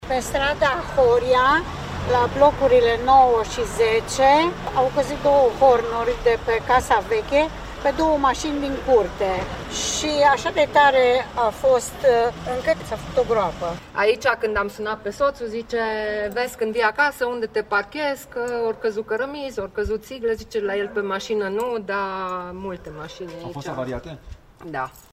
02.-voxuri-masini.mp3